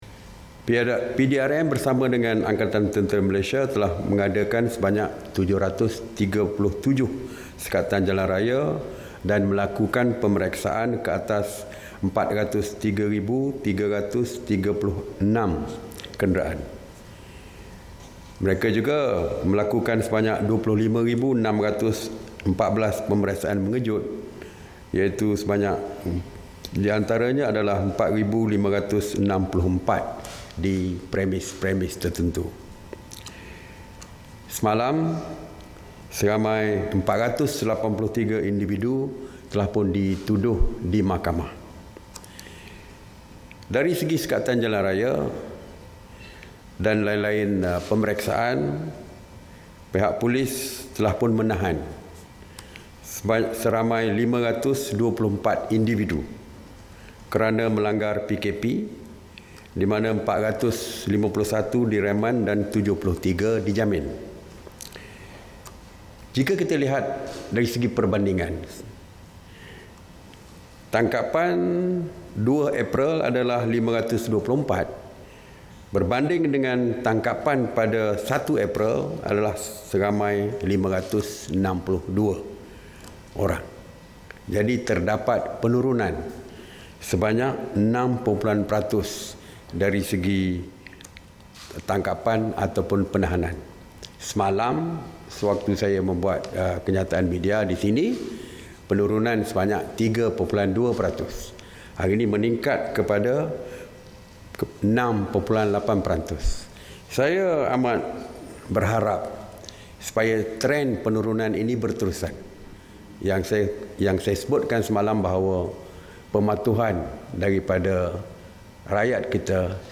Ikuti sidang media Menteri Kanan (Keselamatan), Datuk Seri Ismail Sabri Yaakob.